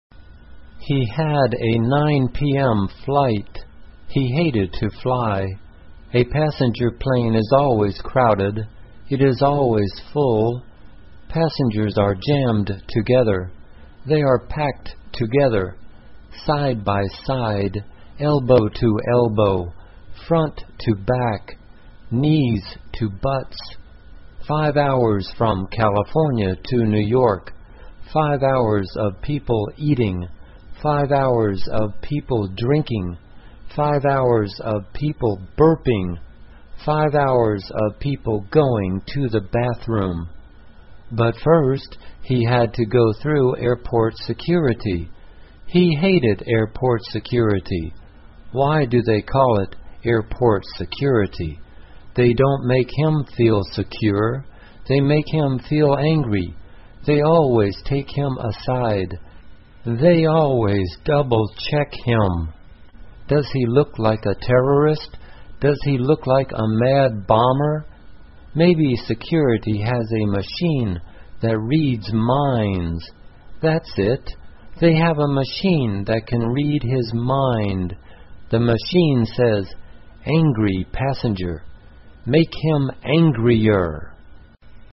慢速英语短文听力 他讨厌坐飞机 听力文件下载—在线英语听力室